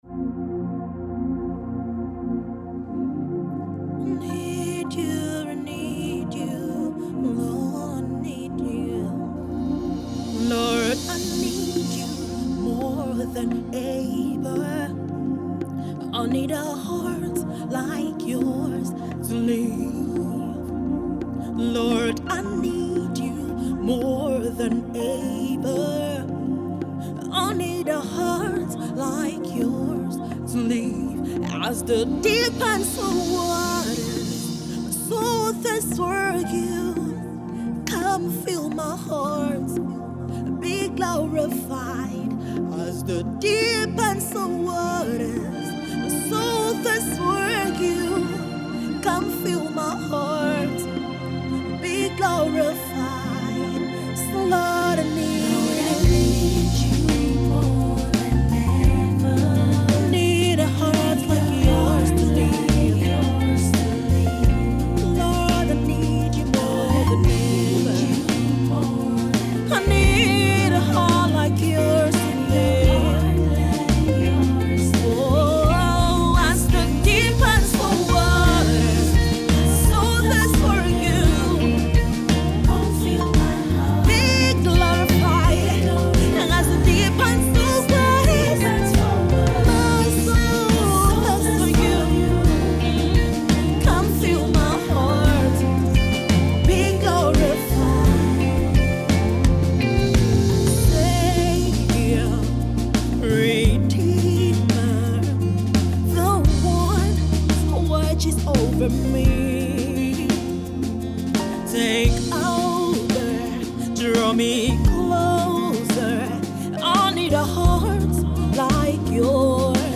heartfelt song of prayer